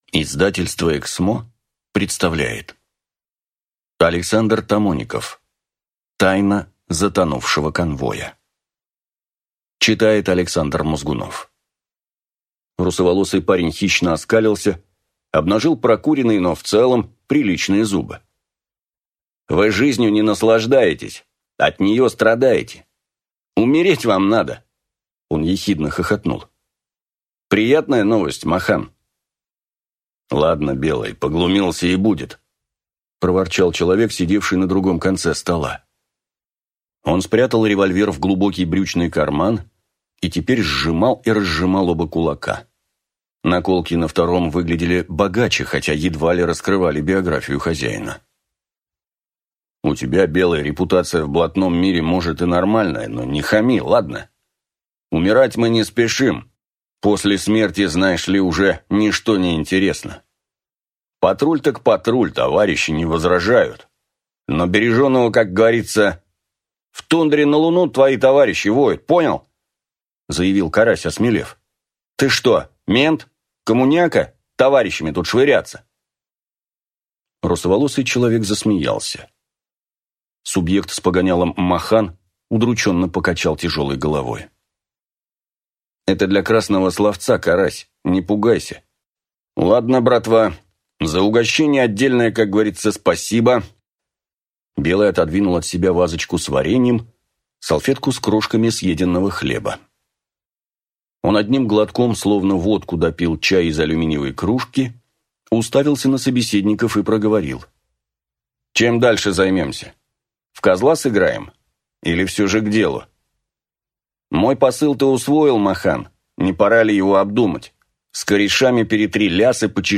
Аудиокнига Тайна затонувшего конвоя | Библиотека аудиокниг